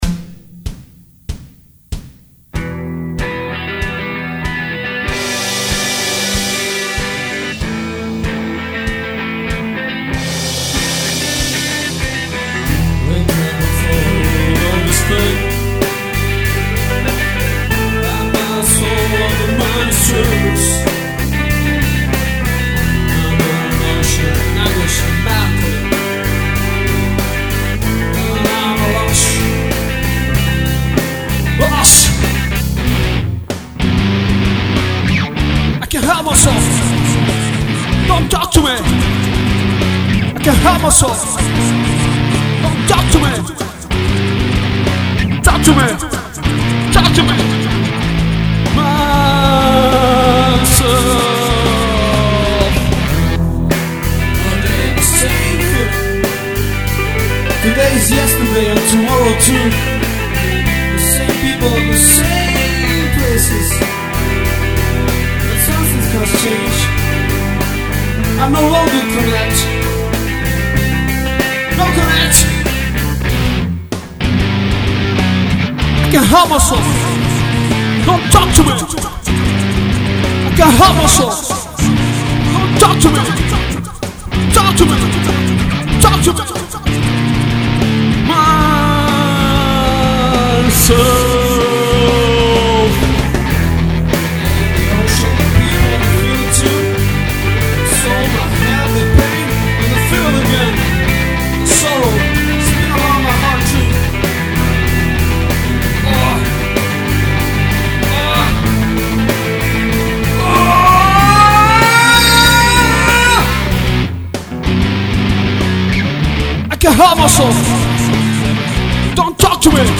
The song was recorded at my home with my notebook, POD X3 Live as sound procesing and input, Adobe audition 3 for software. Drums: EZDrummer (I did the Midi programing) Guitars: the three of them were recorded with my CORT M200 with EMG89 Pickup.
Bass: 4Front Bass VSTi Plugin (I did the Midi programing) Voice: my singer did the singing (or at least tried ). Recorded in my living room with Shure SM58 Mic through signal chain in POD X3 Live. Chorus, reberb and Echo effects added in Adobe Audition standard effects.
So if you read all of the above you understand that this is not Profesional recording.